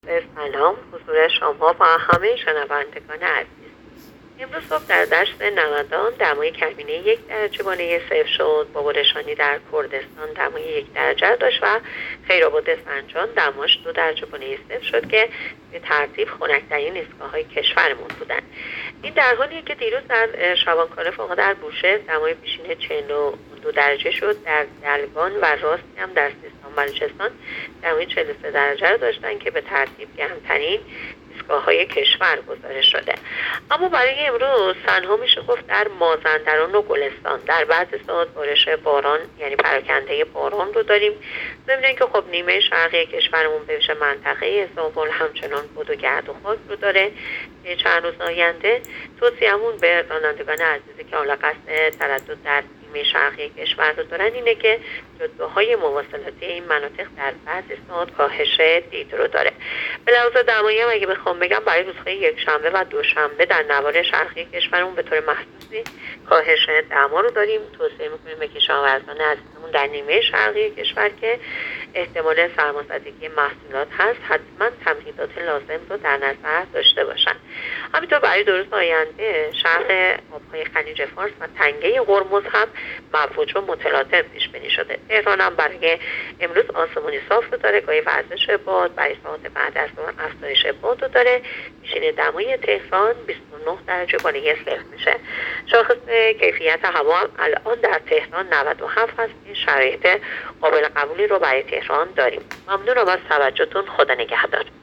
گزارش رادیو اینترنتی پایگاه‌ خبری از آخرین وضعیت آب‌وهوای دوازدهم مهر؛